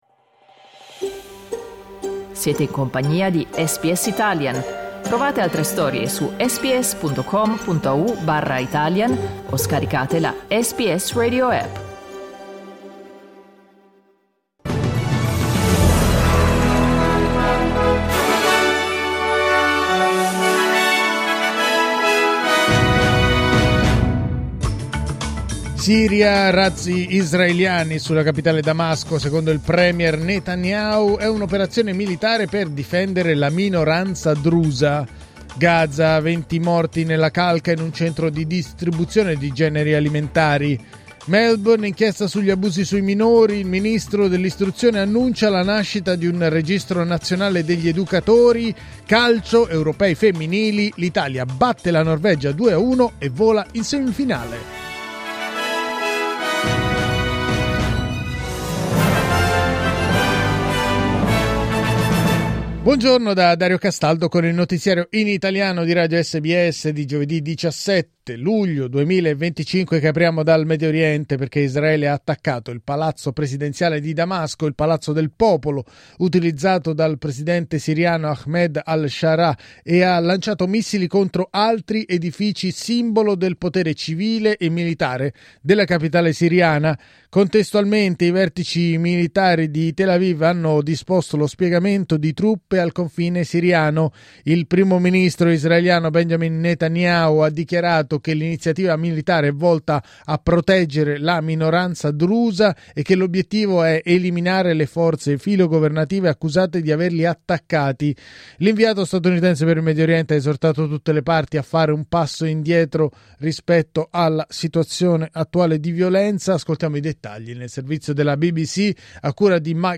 Giornale radio giovedì 17 luglio 2025
Il notiziario di SBS in italiano.